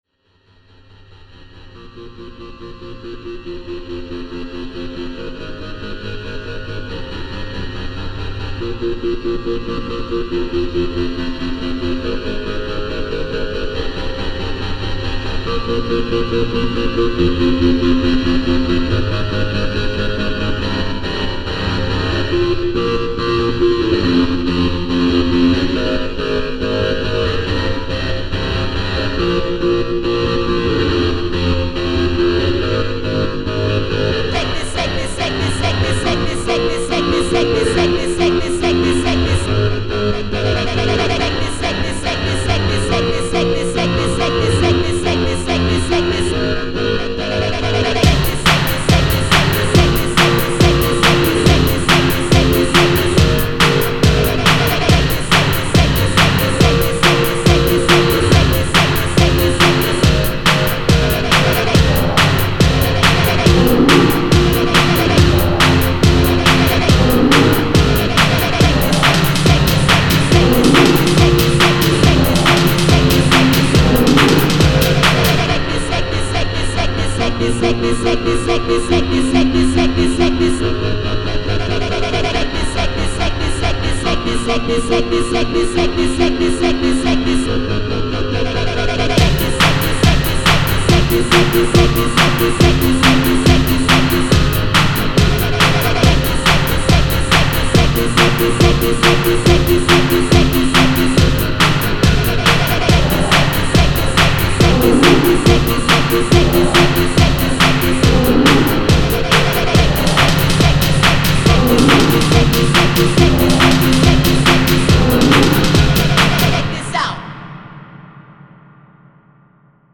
Longer, and higher quality.
The people saying take this over & over... well, the song would still sound good without them.